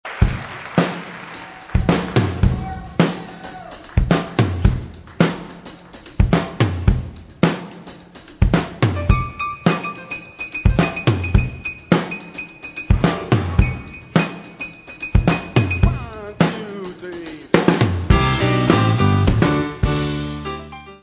piano
baixo
bateria.
Music Category/Genre:  Pop / Rock